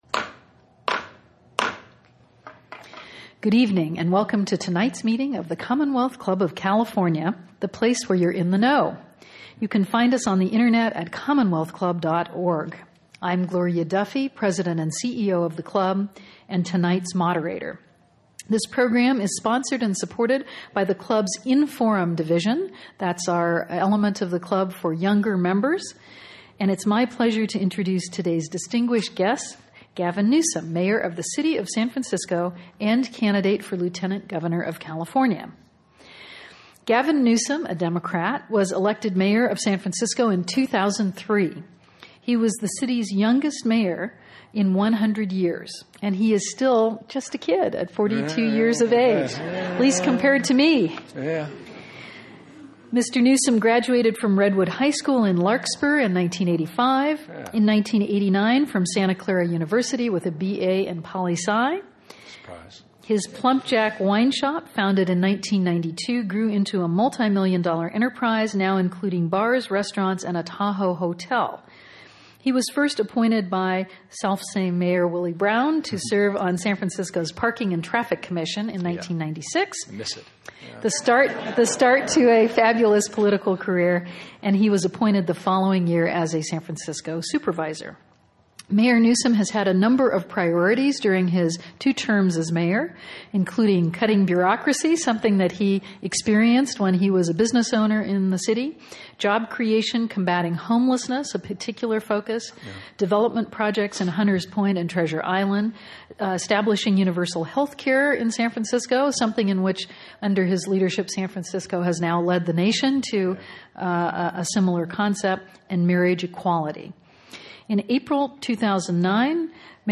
Gavin Newsom: San Francisco's Mayor in Conversation